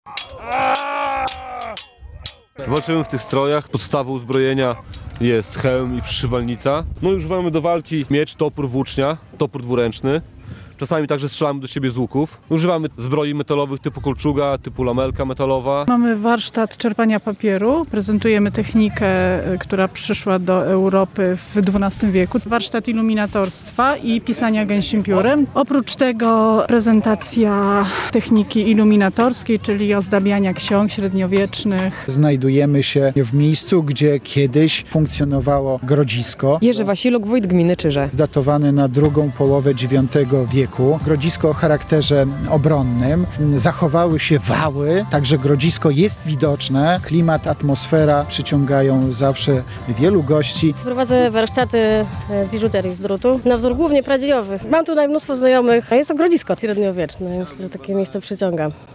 Festyn archeologiczny w Zbuczu - relacja